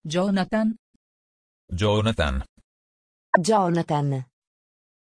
Pronunciación de Joonatan
pronunciation-joonatan-it.mp3